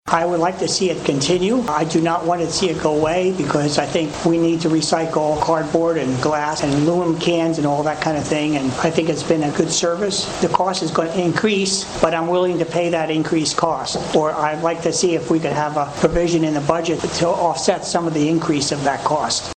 (STURGIS) – The topic of keeping recycling in Sturgis was addressed again during the August 13 City Commission meeting.
During the Citizens Comments portion of the meeting, several members of the audience shared their thoughts on the matter of recycling.